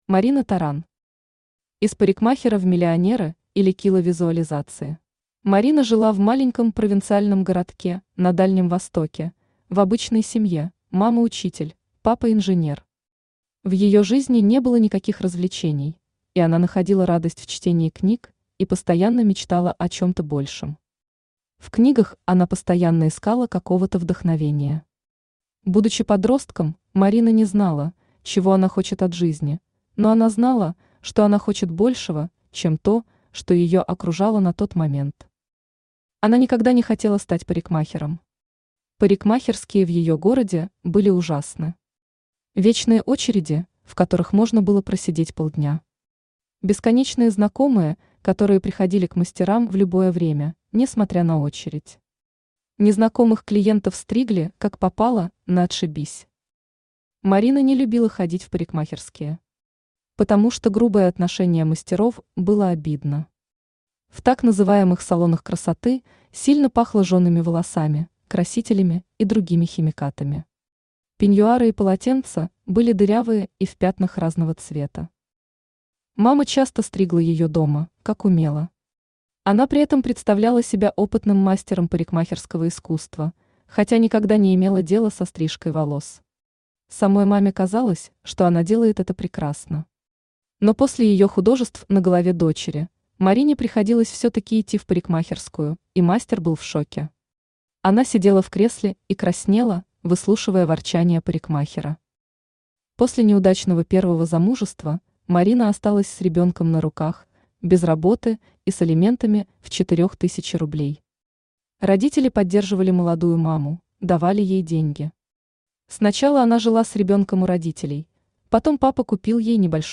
Аудиокнига Из парикмахера в миллионеры, или Cила визуализации | Библиотека аудиокниг
Aудиокнига Из парикмахера в миллионеры, или Cила визуализации Автор Марина Таран Читает аудиокнигу Авточтец ЛитРес.